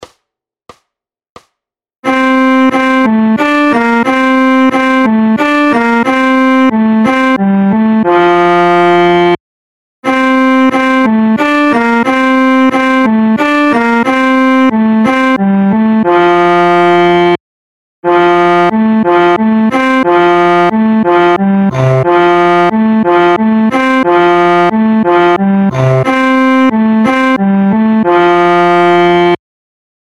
Noty na violoncello.
Hudební žánr Vánoční písně, koledy